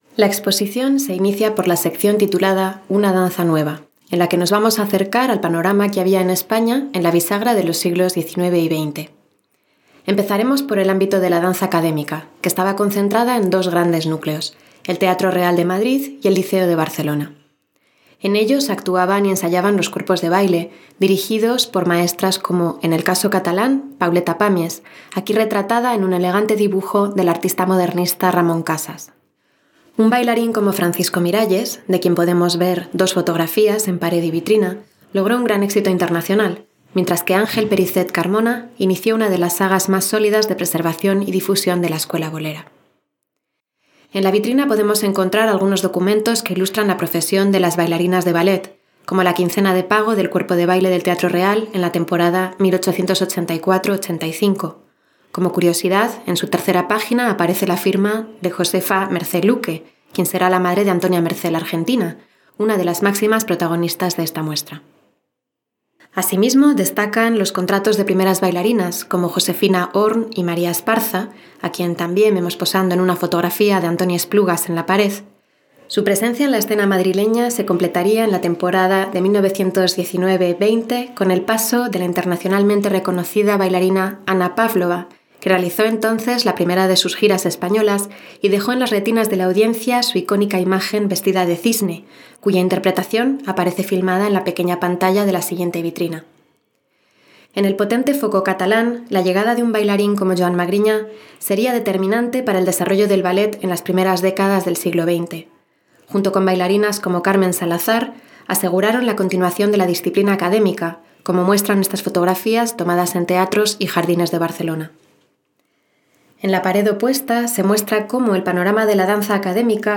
Audioguía de la sala Alumnas de Narcís Masó en la playa, Santander, 25 de septiembre de 1919.